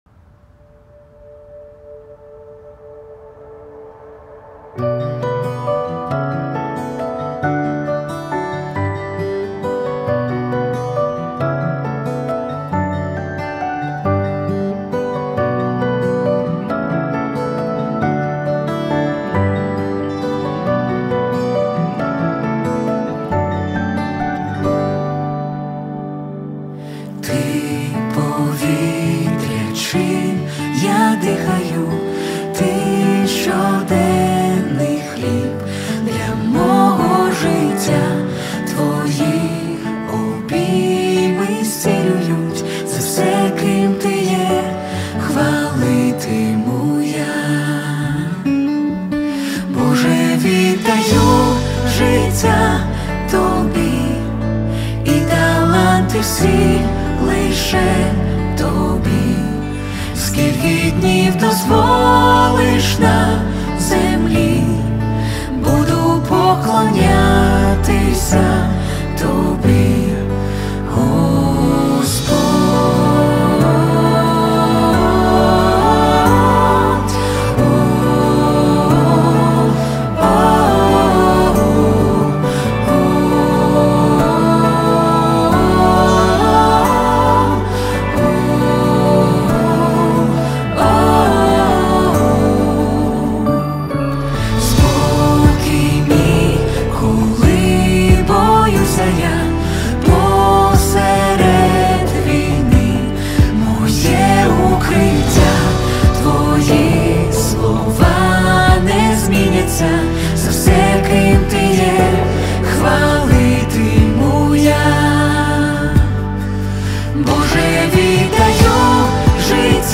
4522 просмотра 1094 прослушивания 314 скачиваний BPM: 68